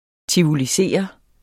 Udtale [ tivoliˈseˀʌ ]